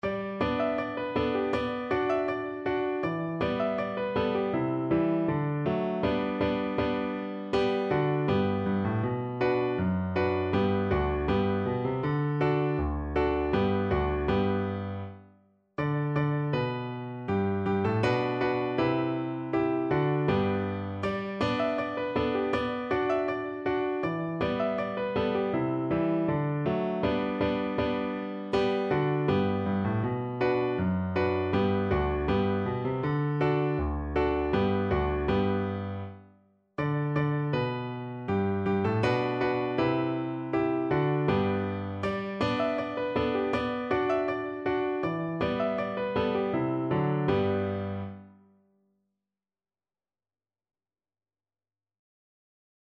Steadily =c.80
2/4 (View more 2/4 Music)